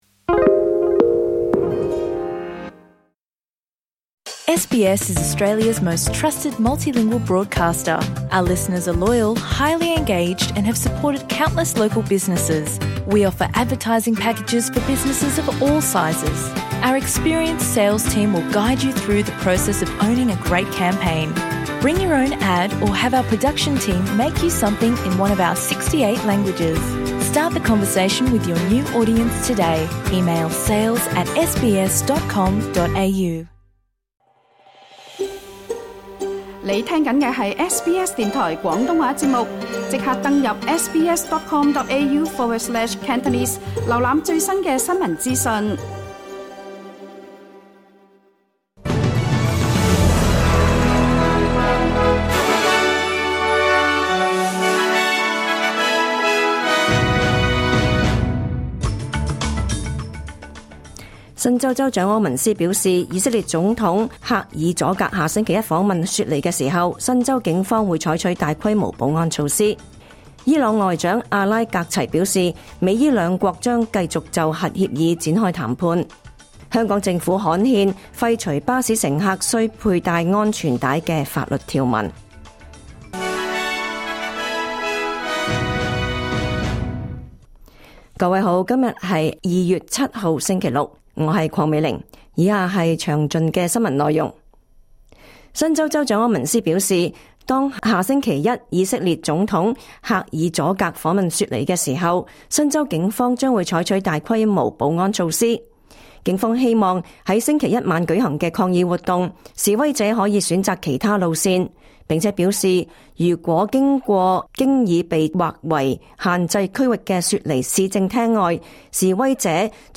2026 年 2 月 7 日 SBS 廣東話節目詳盡早晨新聞報道。